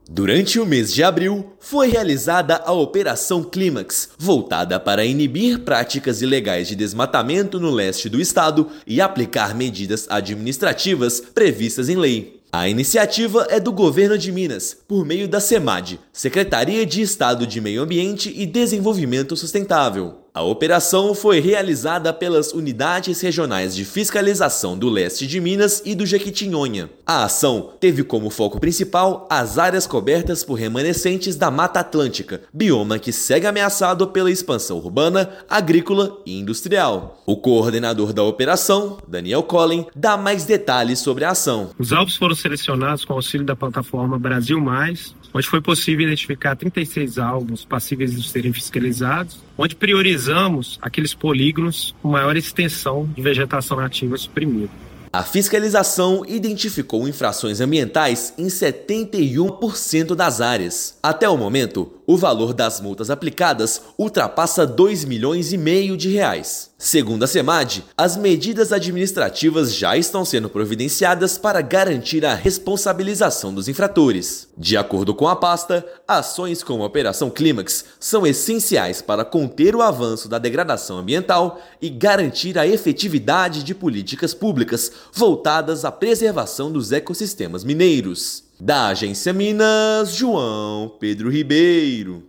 Ação mira 36 alvos, aplica mais de R$ 2,5 milhões em multas e reforça o compromisso do Estado com a preservação da Mata Atlântica. Ouça matéria de rádio.